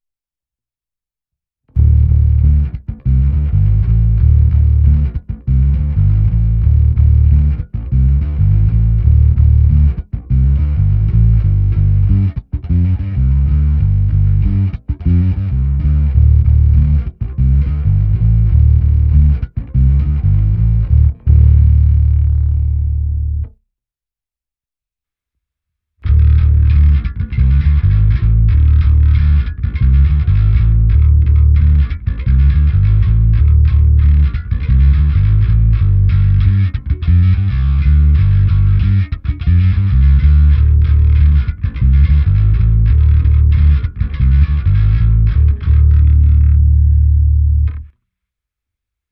Pro srovnání ještě uvádím zkreslenou ukázku ADAMa a mého používaného zvuku s Xkem.